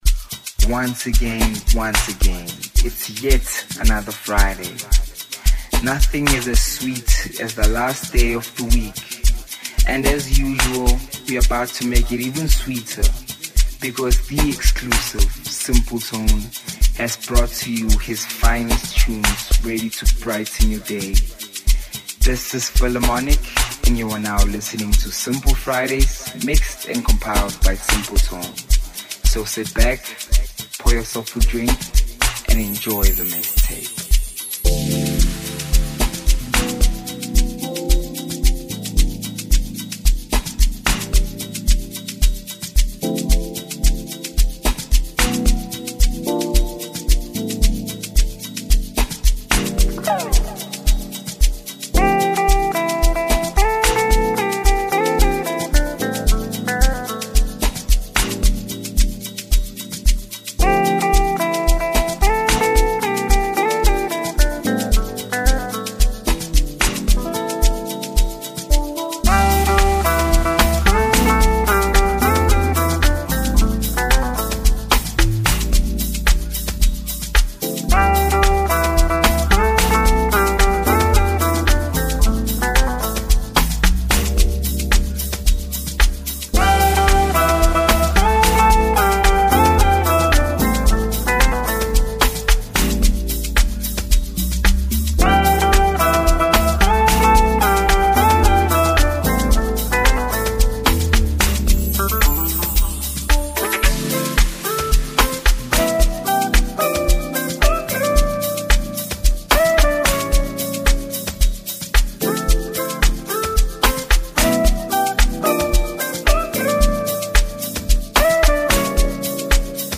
Guitar influenced Amapiano tunes. Voice over